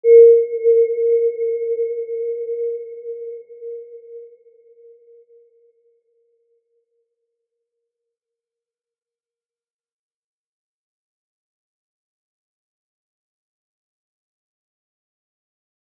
Aurora-C5-p.wav